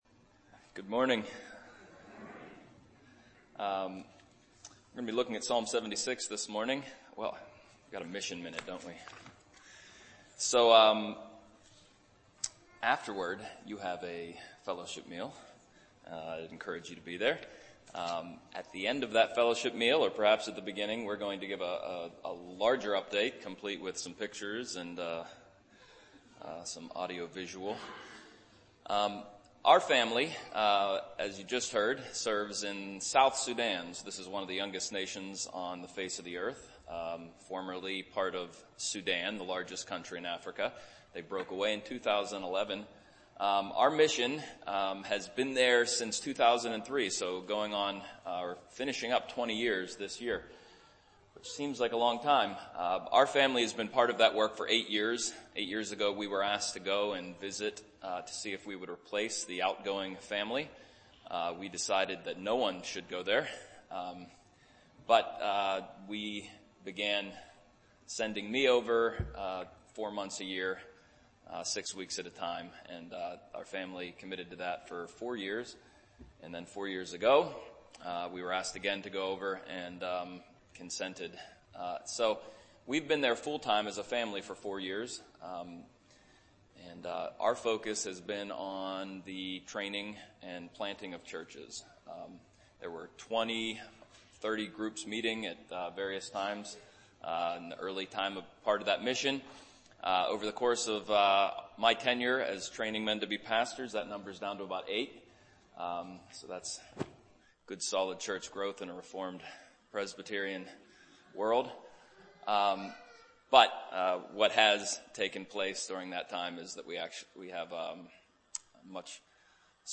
We have a very special guest preacher filling the pulpit for us this Lord’s Day.